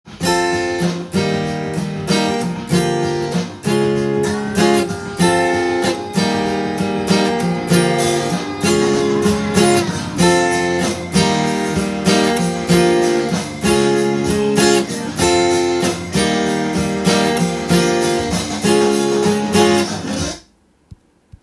イントロからシンコペーションが全ての小節で入っているので、
メトロノームの合わせて裏拍で弾く練習になります。